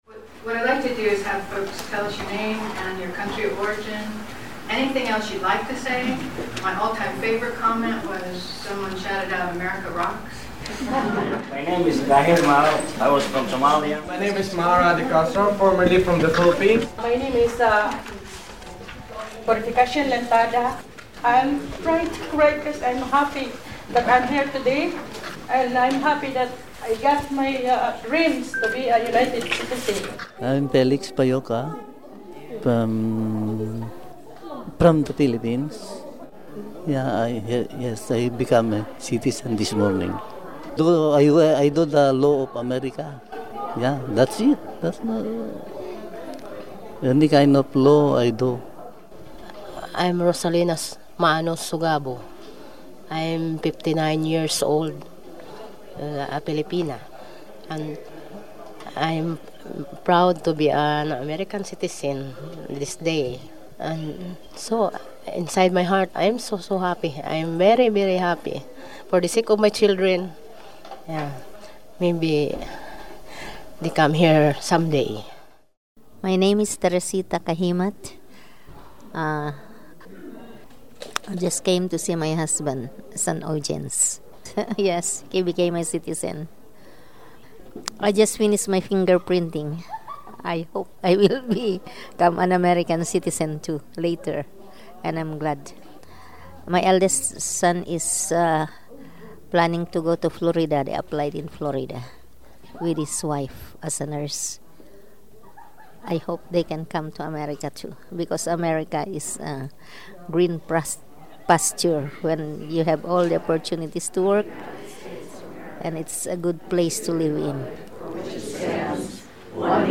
Here, in their own words, are some of the new Americans.